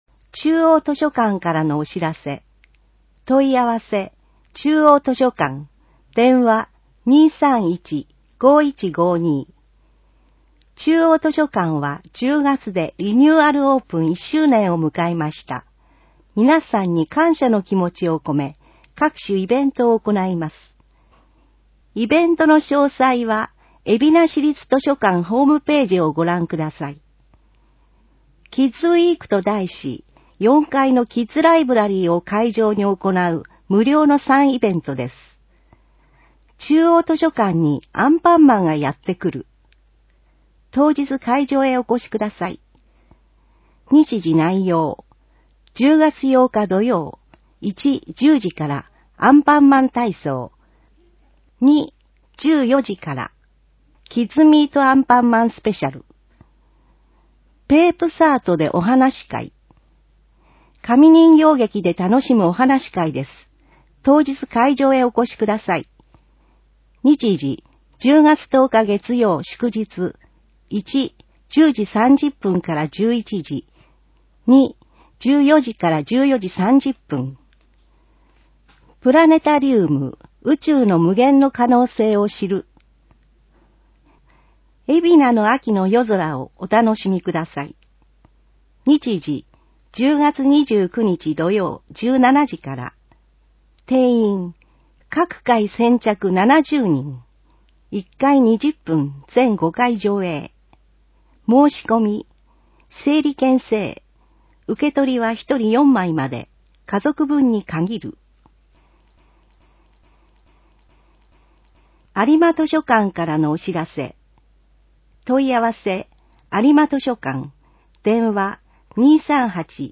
広報えびな 平成28年10月1日号（電子ブック） （外部リンク） PDF・音声版 ※音声版は、音声訳ボランティア「矢ぐるまの会」の協力により、同会が視覚障がい者の方のために作成したものを登載しています。